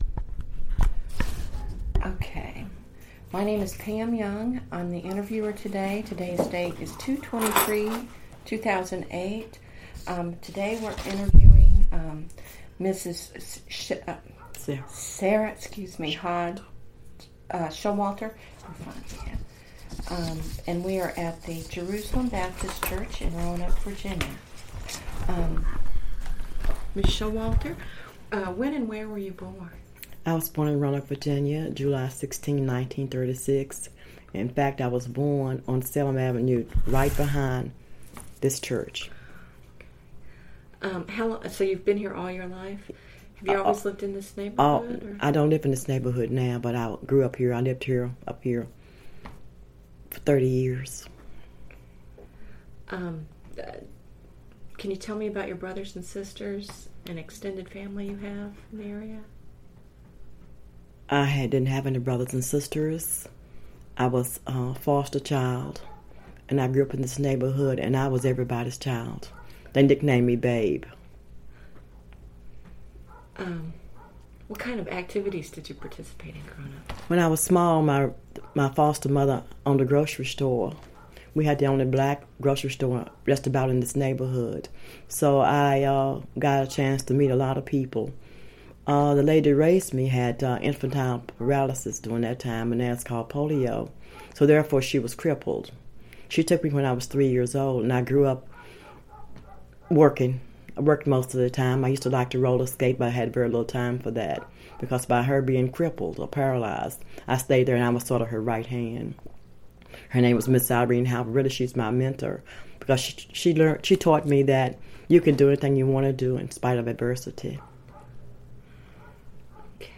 Location: Jerusalem Baptist Church
Neighborhood Oral History Project